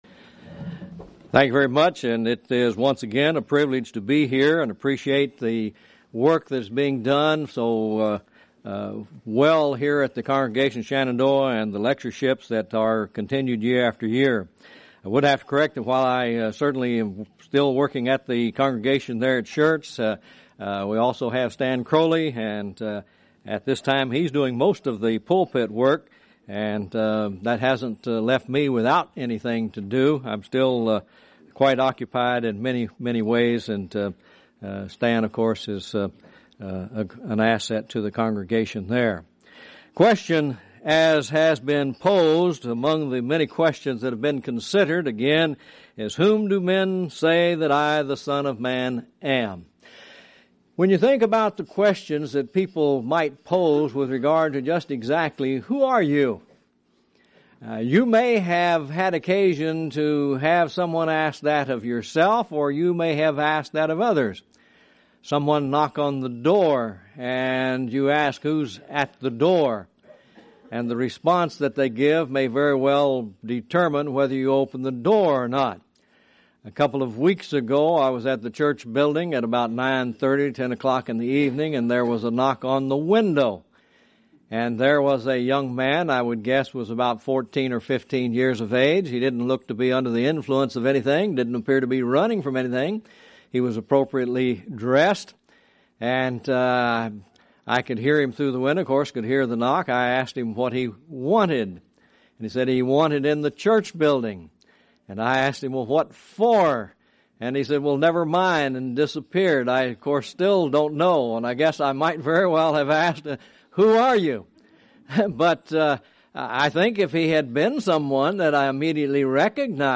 Event: 2003 Annual Shenandoah Lectures Theme/Title: Great Questions in the Bible